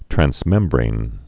(trăns-mĕmbrān, trănz-)